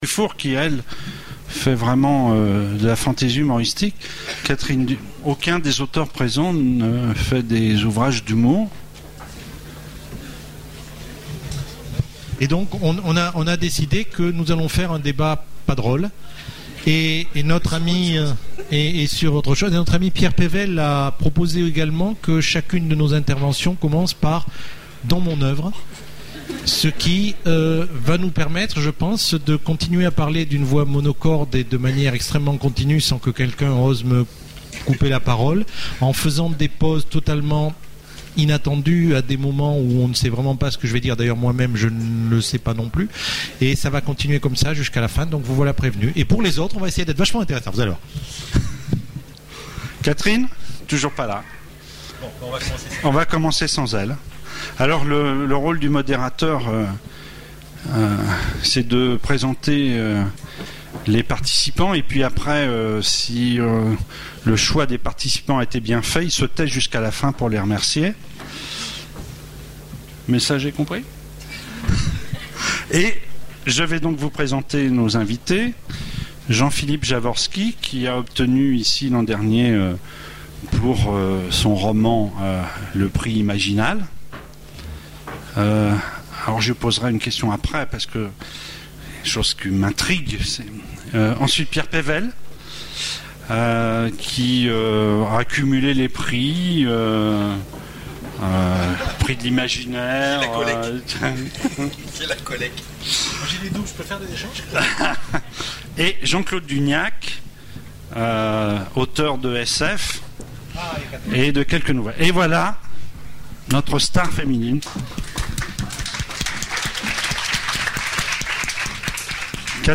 Voici l'enregistrement de la conférence Le rire du troll… La fantasy a parfois de l’humour ! aux Imaginales 2010